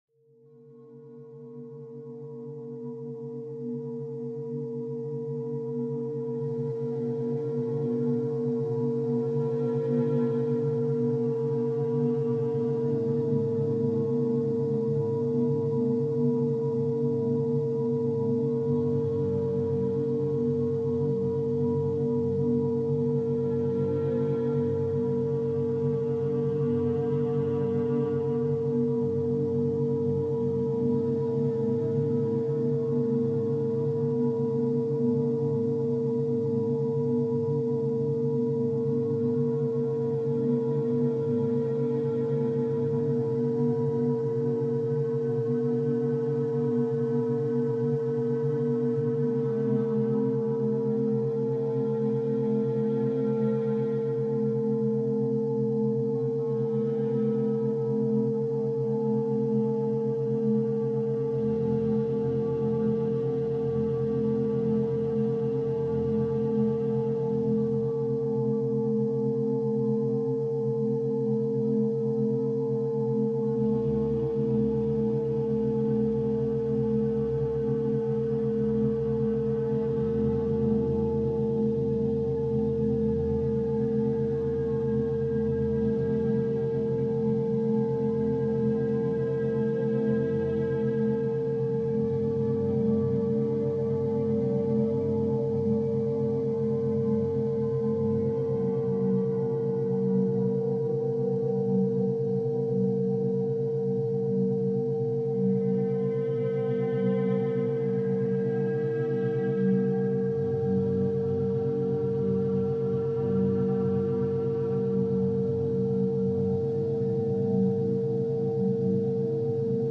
Background Sounds